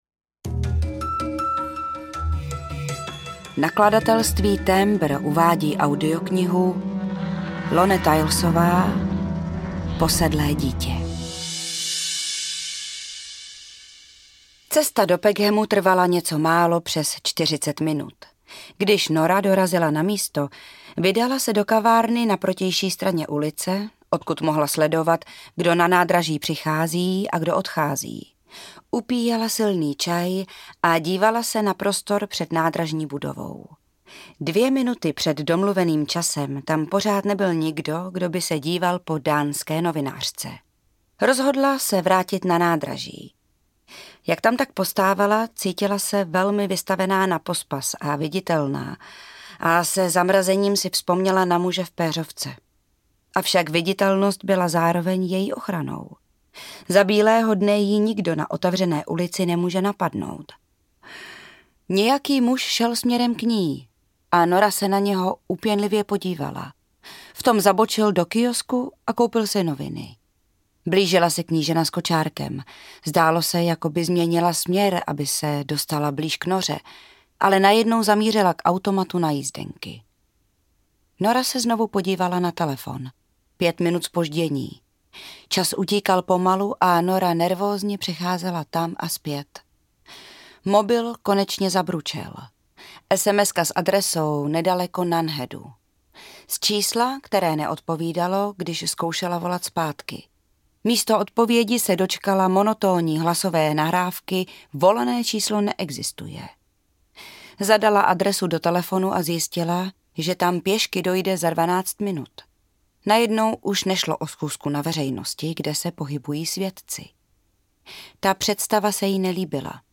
Posedlé dítě audiokniha
Ukázka z knihy